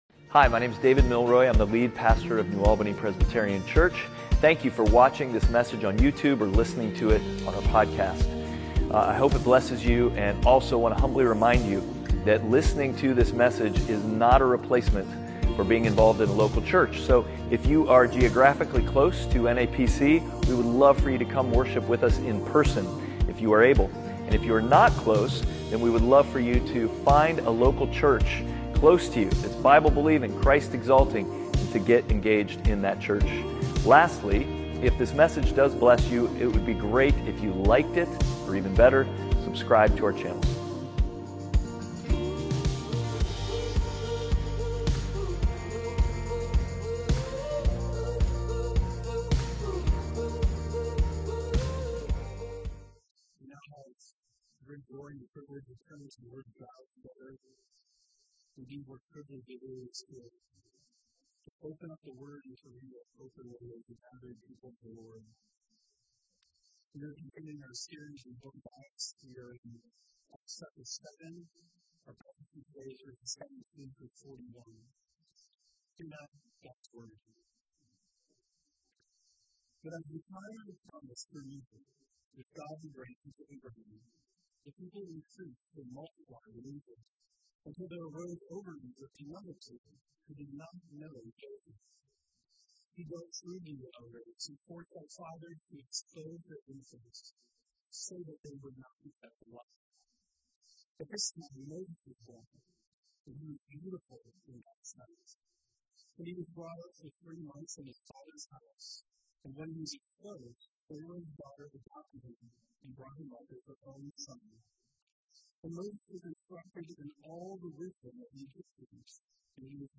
Passage: Acts 7:7-41 Service Type: Sunday Worship